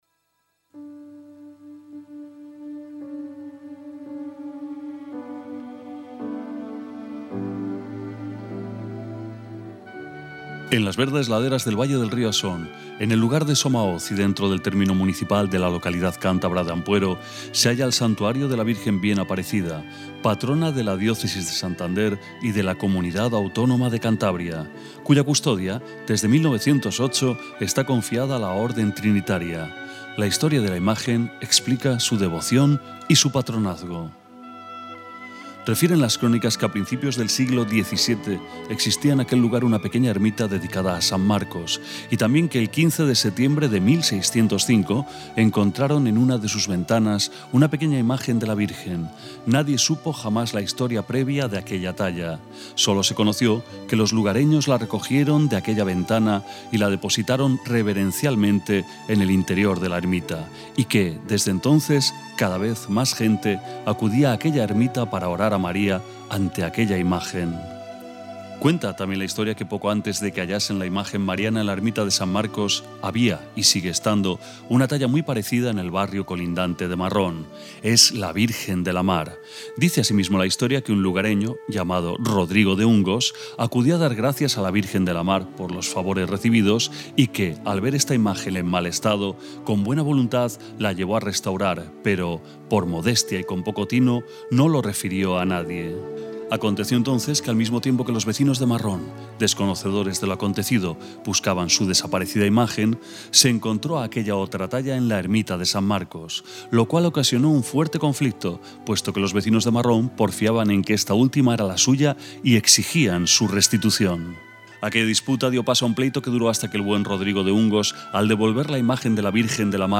Audioguías
Audio-en-espanol-voz-masculina-con-musica-copia.mp3